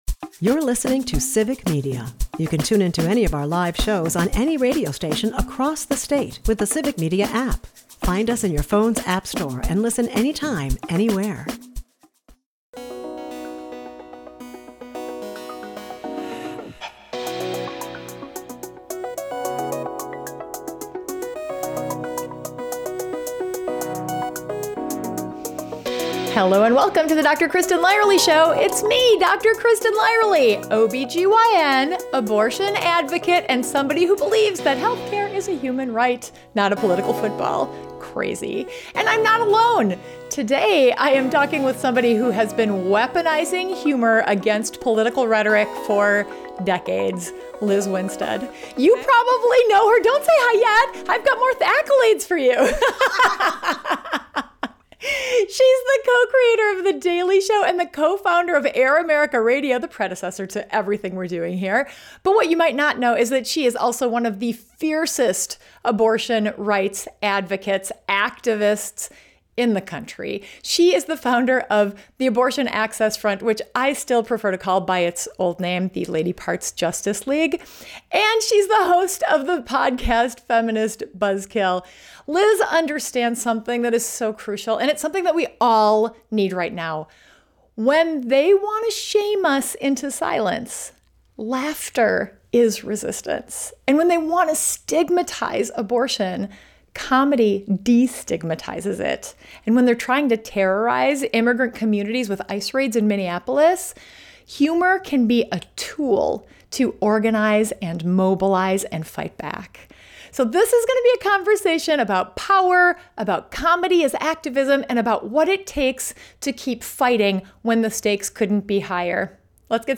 Guests: Lizz Winstead
This is a fearless conversation about what it takes to keep fighting when the stakes couldn't be higher.